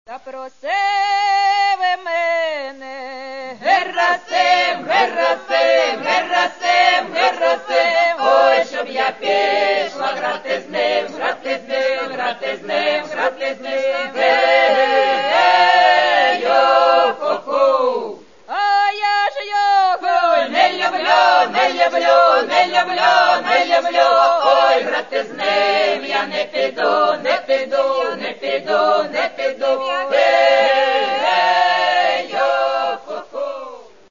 Каталог -> Народна -> Автентичне виконання